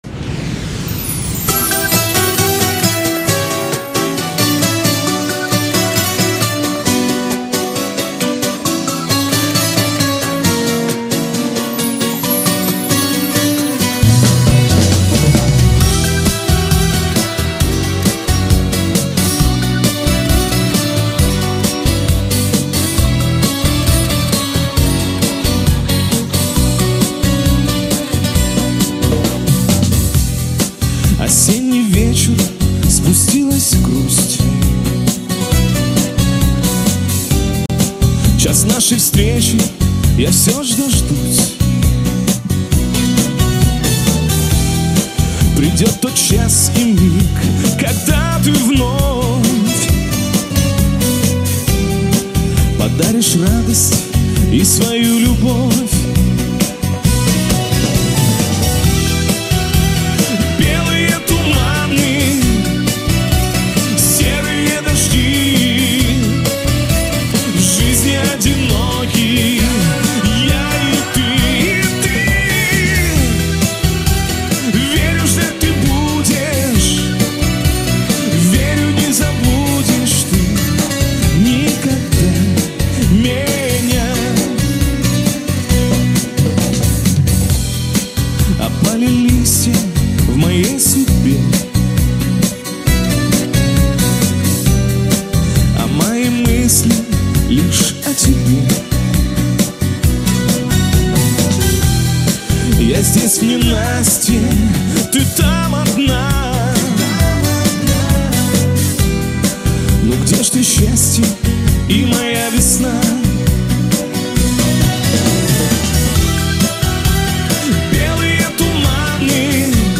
Всі мінусовки жанру Shanson
Плюсовий запис